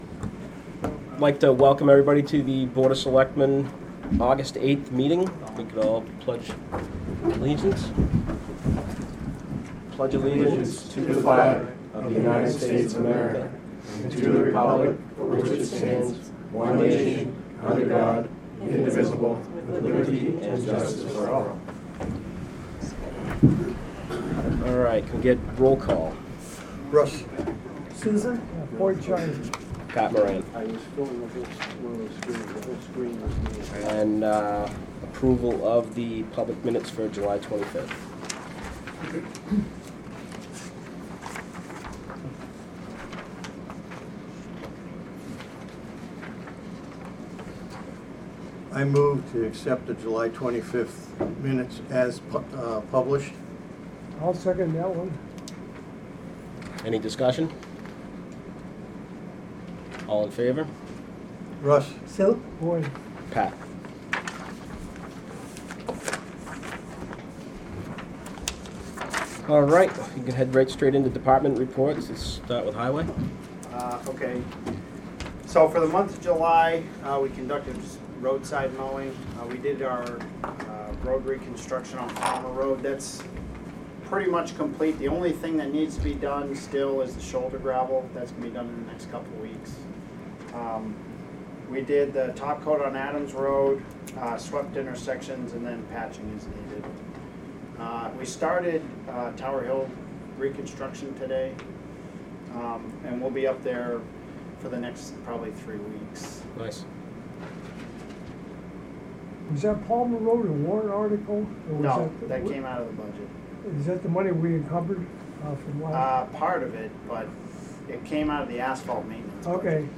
Audio recordings of committee and board meetings.
Board of Selectmen Meeting